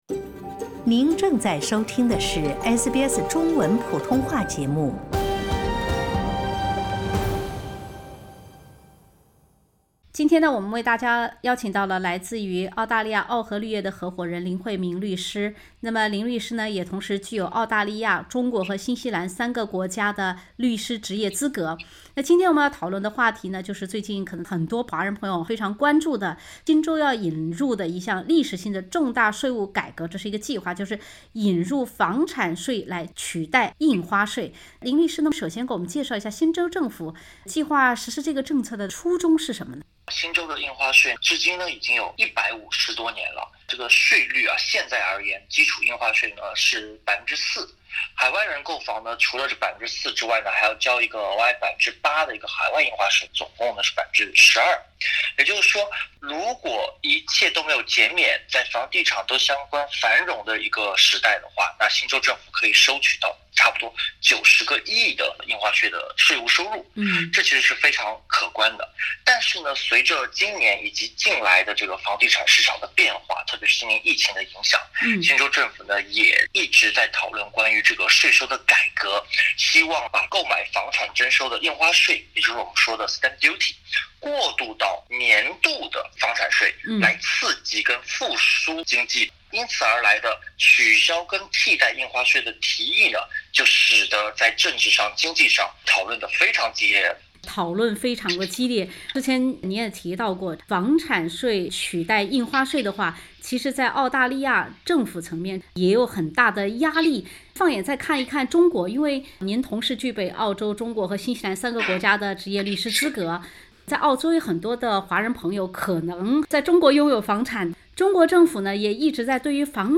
欢迎收听本台记者带来的采访报道。